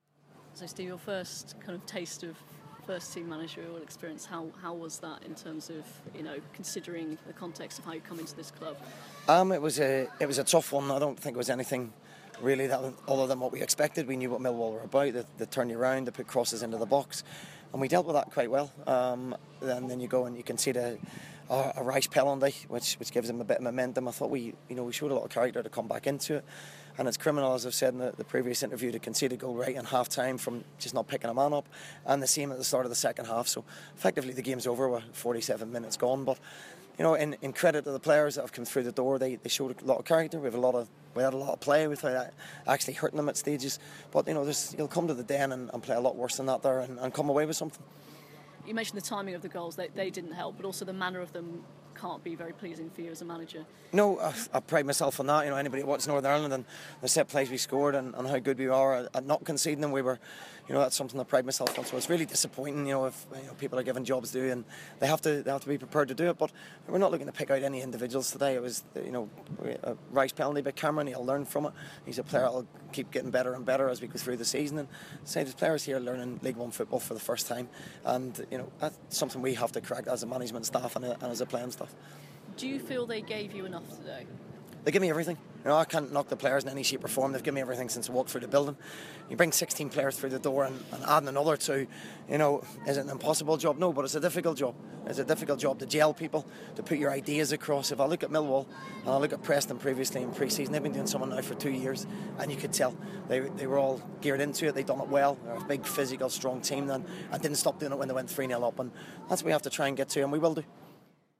talks to BBC Radio Manchester following his sides disappointing 3-0 opening day defeat to Millwall.